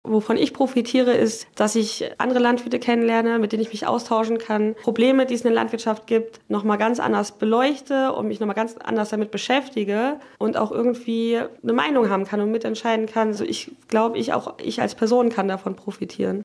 Profitieren werde sie trotzdem, war sie sich im Radio Siegen-Interview sicher.